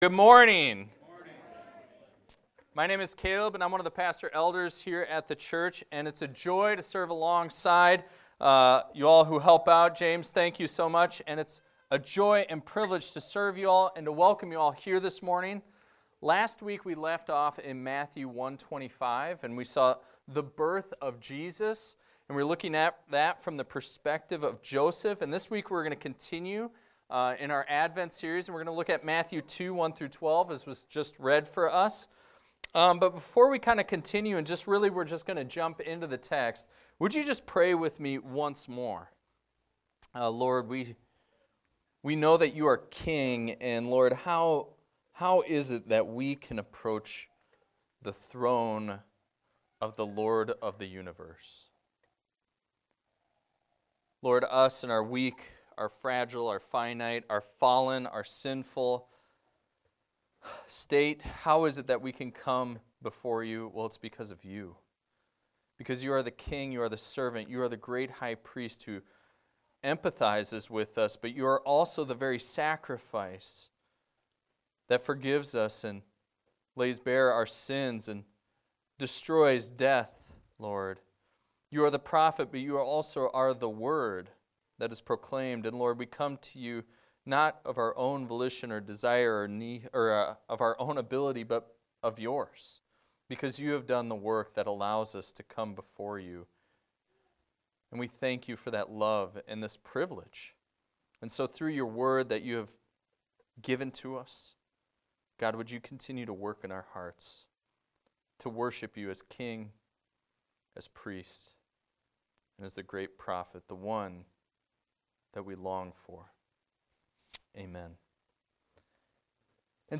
A sermon about the Praise of the Magi from Matthew 2:1-12